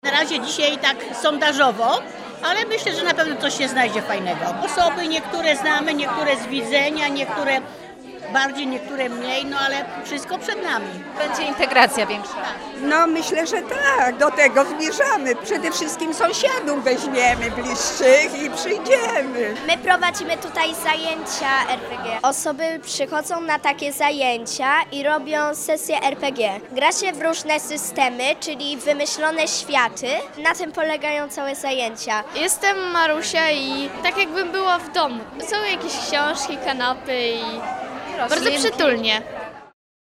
W uroczystym otwarciu wzięli udział mieszkańcy osiedla. Zapytaliśmy ich o wrażenia i plany dotyczące tej przestrzeni.
04_mieszkancy.mp3